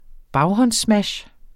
Udtale [ ˈbɑwhʌns- ]